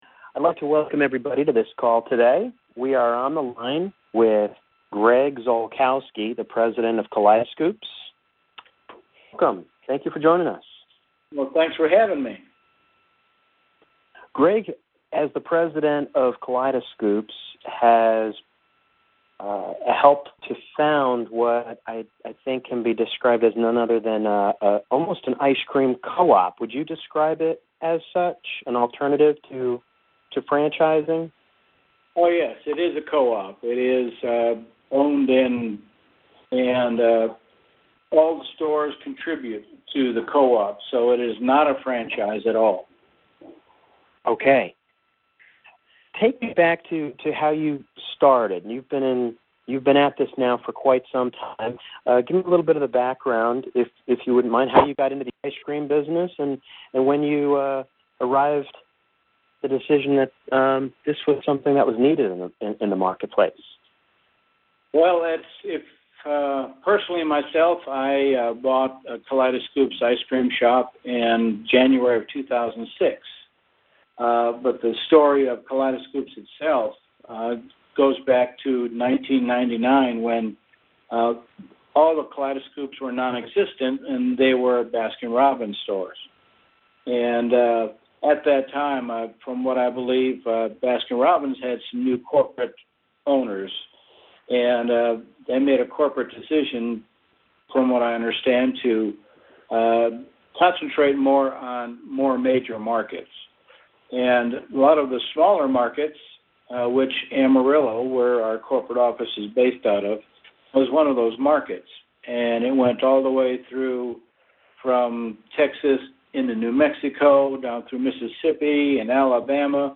KaleidoScoops Interview